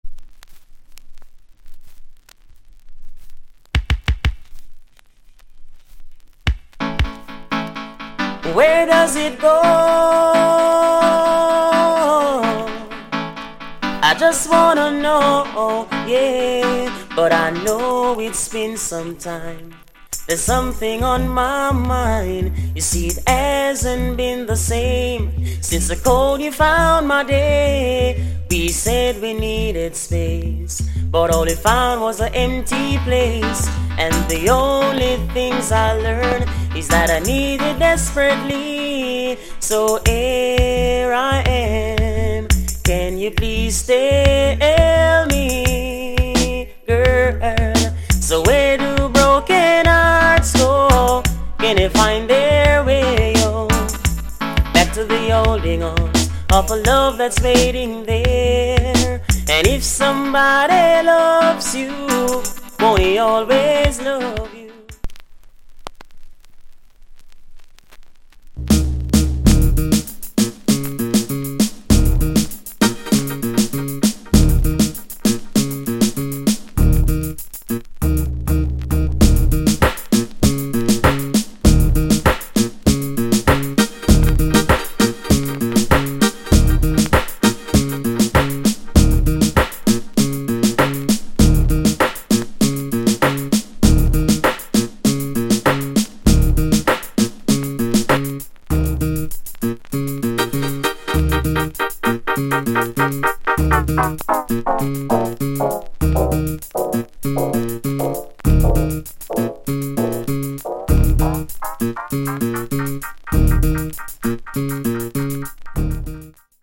* Good Vocal.